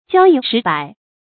教一识百 jiāo yī shí bǎi
教一识百发音